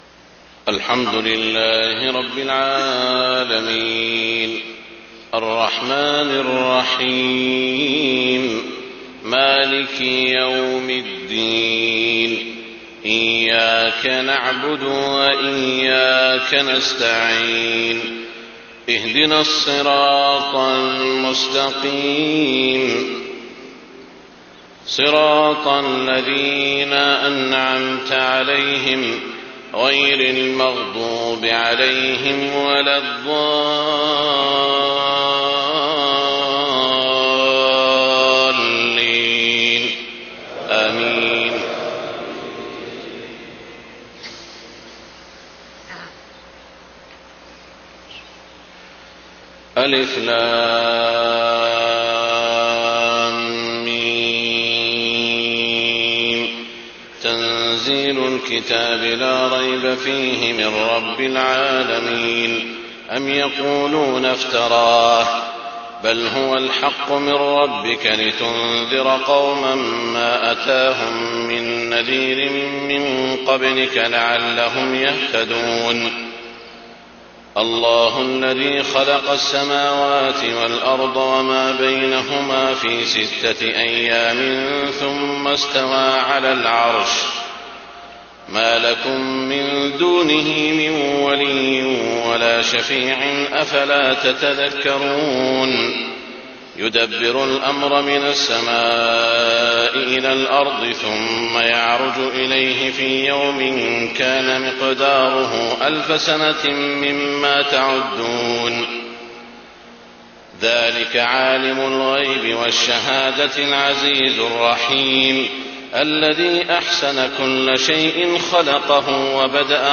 صلاة الفجر 9 شعبان 1430هـ سورتي السجدة و الإنسان > 1430 🕋 > الفروض - تلاوات الحرمين